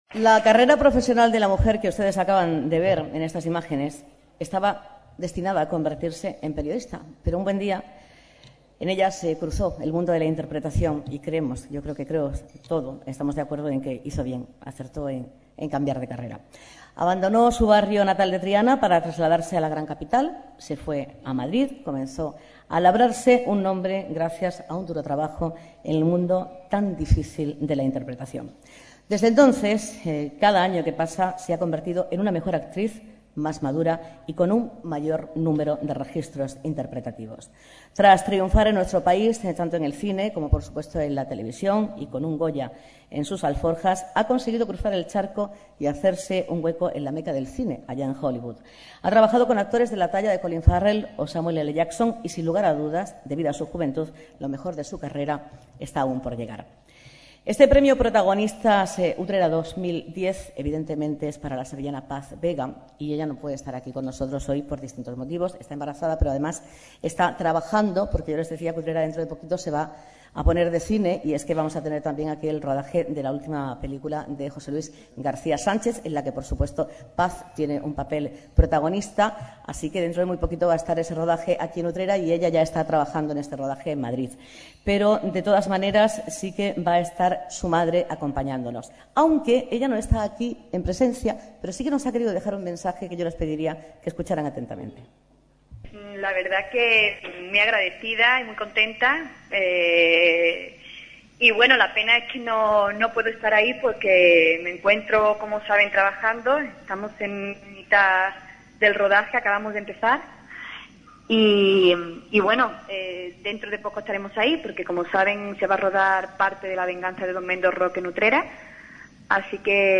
11 июня состоялось церемония вручения наград "Protagonistas Utrera 2010", организованная Punto Radio Utrera.
Презентация награды и сообщение Пас Веги